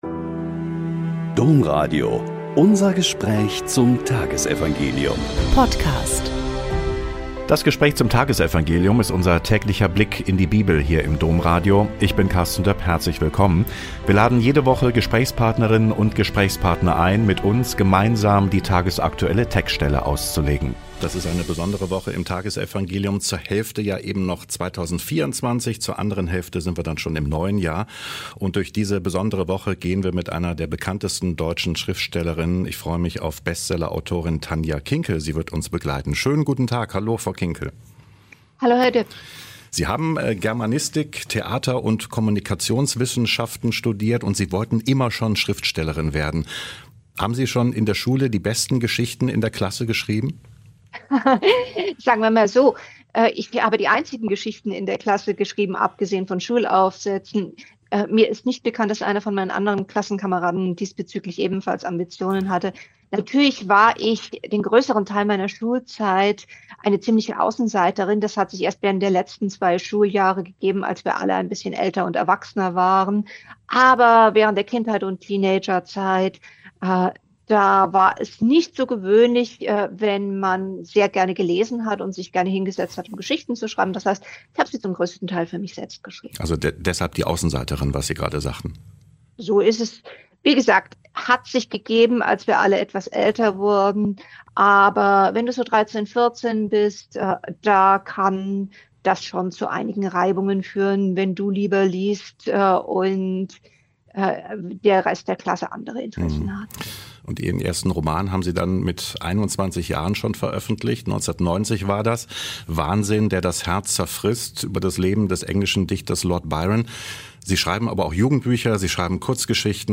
Lk 2,36-40 - Gespräch mit Dr. Tanja Kinkel ~ Blick in die Bibel Podcast